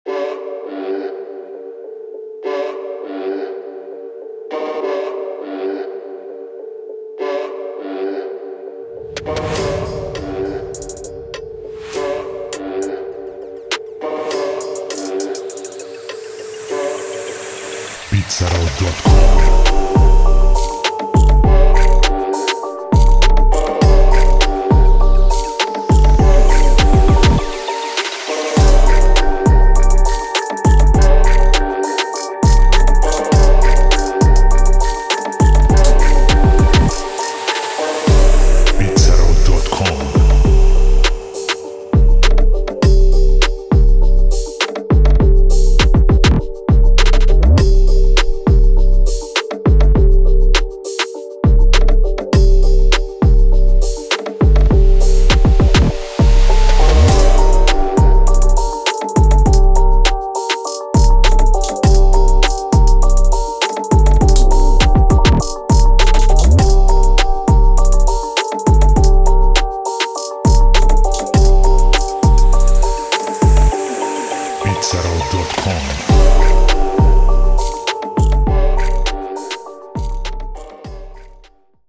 دسته و ژانر: Trap
سبک و استایل: اجتماعی،گنگ
سرعت و تمپو: 101 BPM